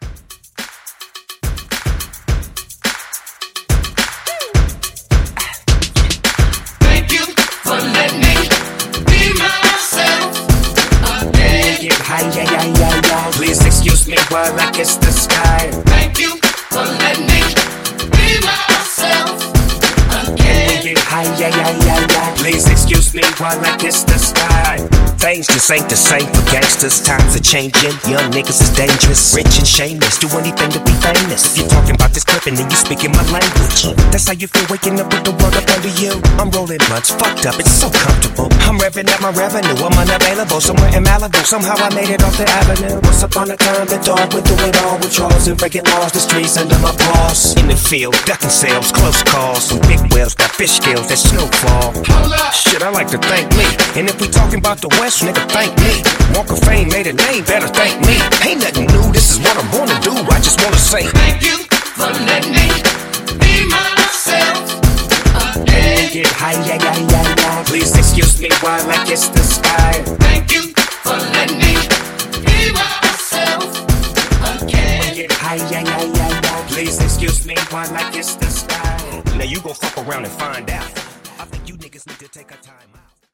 Genres: RE-DRUM , TOP40
Clean BPM: 104 Time